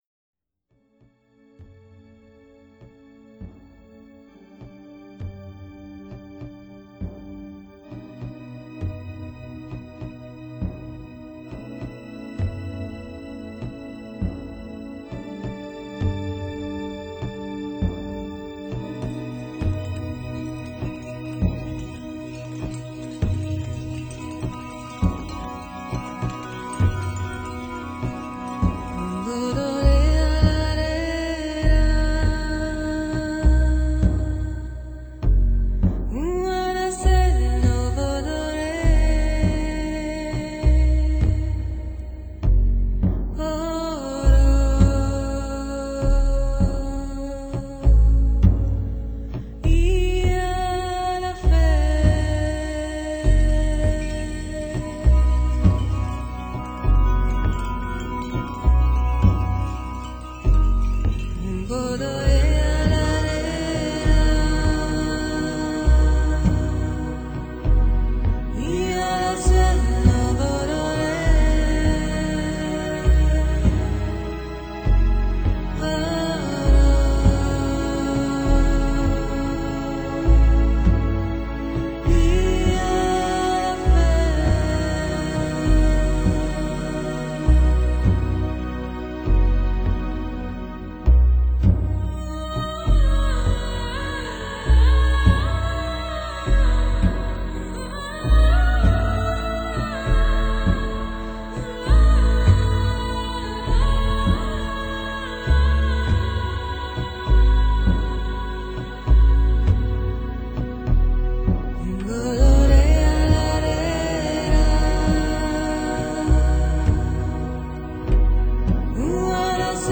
钢琴，在低沉的号角里响起，轻柔，舒缓，淡淡的思念，交织在琴音里，揉碎电子音乐里，飘荡，没有边际。